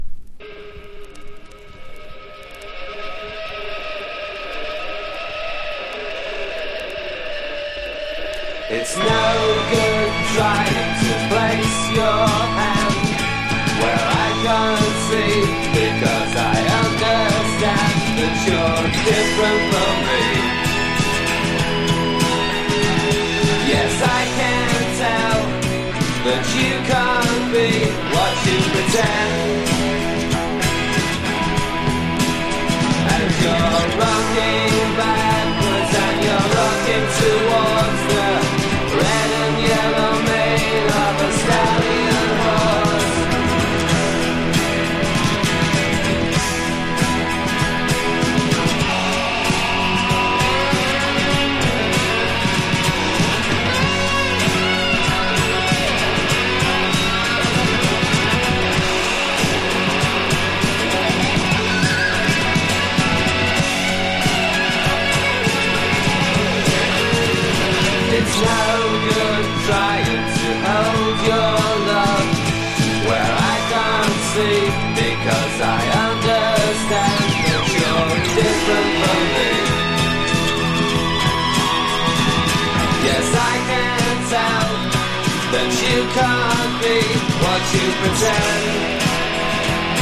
サイケ～アシッド感の強い内容で、彼の影響を受けた当時の若手バンドが見事カヴァー。